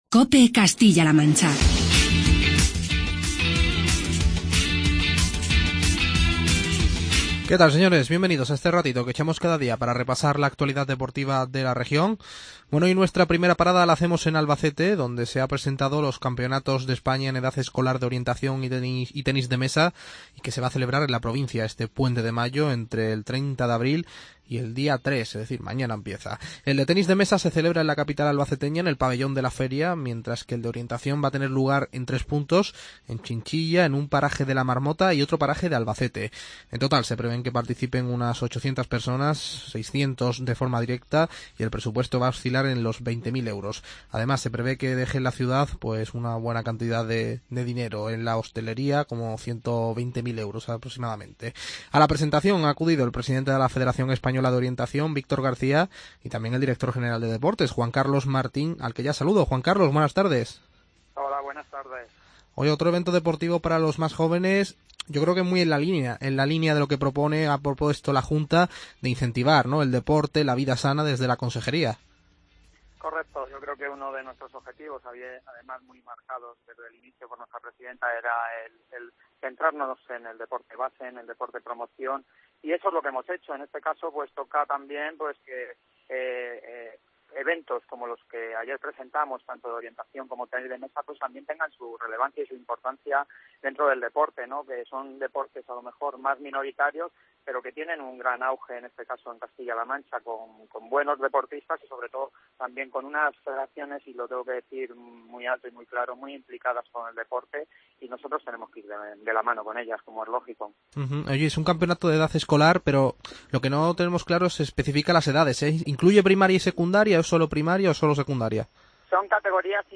Charlamos con Juan Carlos Martín, Director General de Deportes de Castilla-La Mancha